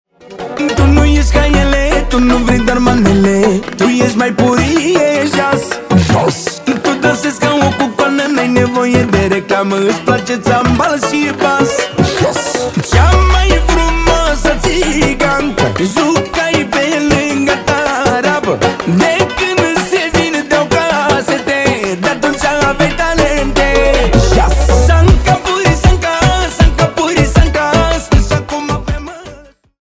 Categorie: Manele